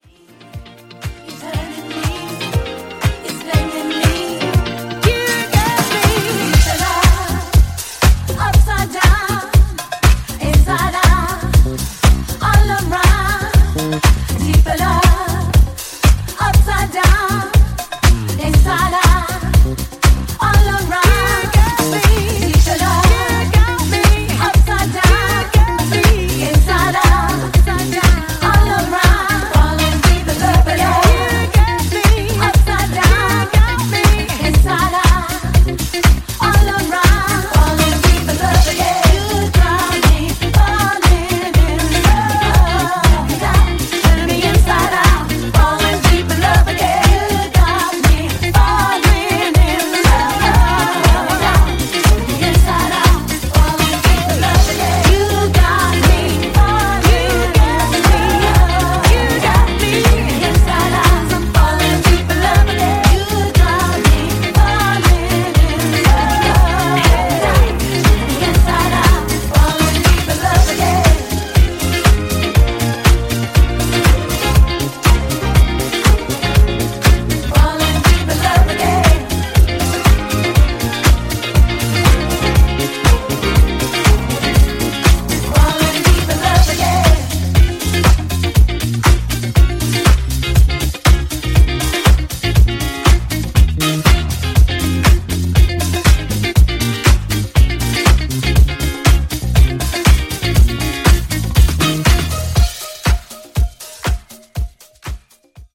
> HOUSE・TECHNO